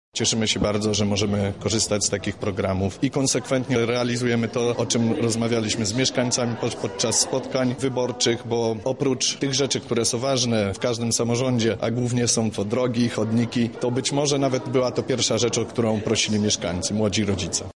O tym, jak duże znaczenie ma to dla lokalnych społeczności mówi Paweł Karczmarczyk, burmistrz Poniatowej: